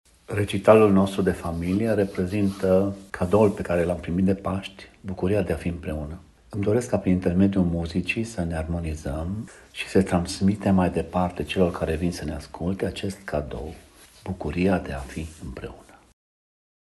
interviuri, pentru Radio Timișoara, cu pianiștii